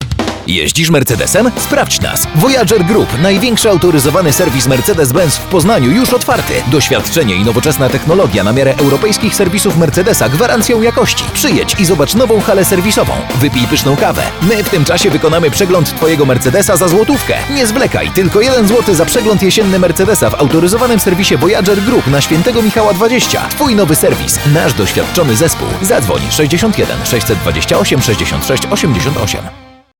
Male 30-50 lat
Spot_1_final.mp3